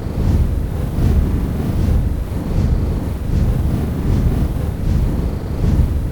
DragonFlying.wav